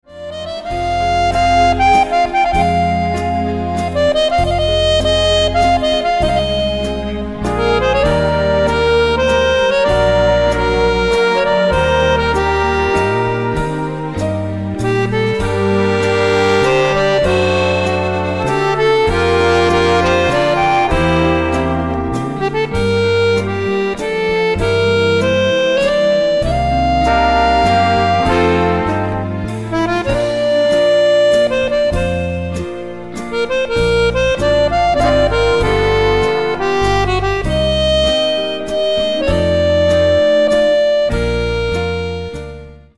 VALZER LENTO  (3.32)